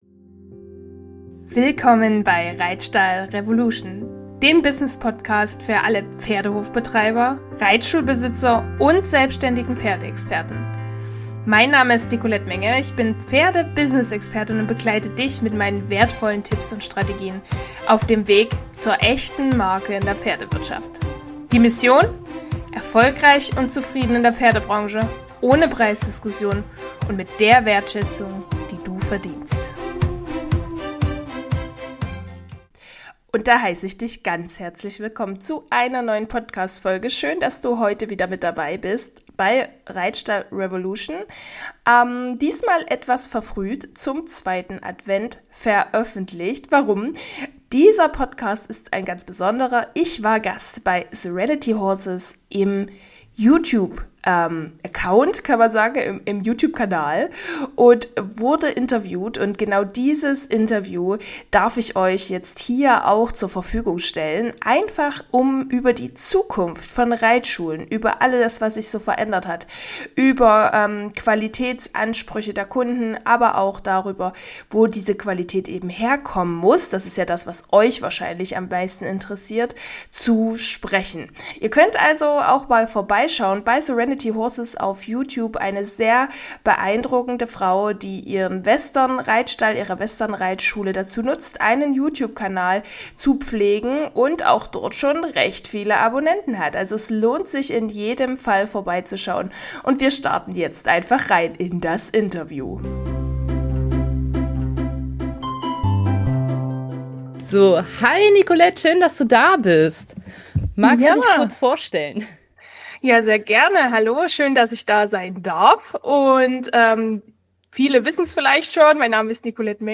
Folge 30 - Im Talk mit Serenity Horses ~ reitstallrevolution - So geht Pferdewirtschaft heute Podcast